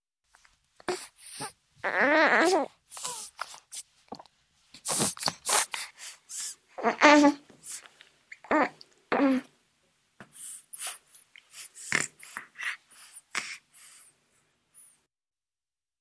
Звуки сосания груди